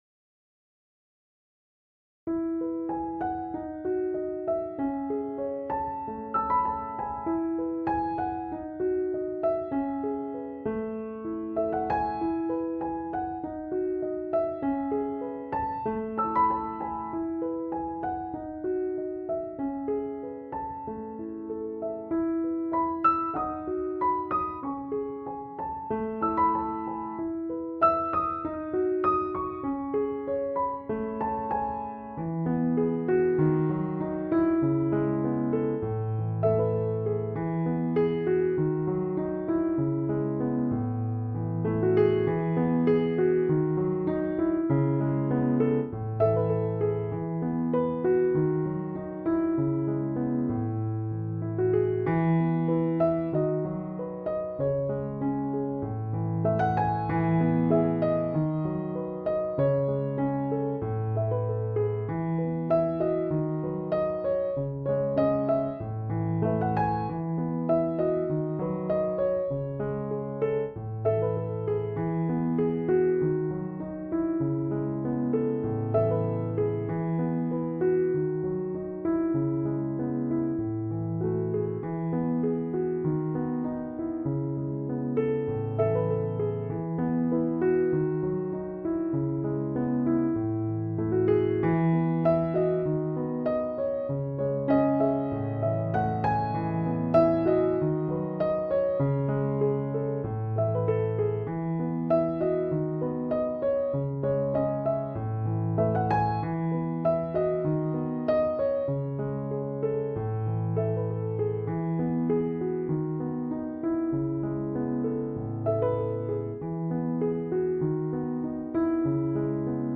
KGnwRXNnz4e_musica-para-poemas-instrumental-para-recitar.mp3